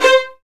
VIOLINS C5.wav